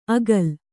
♪ agal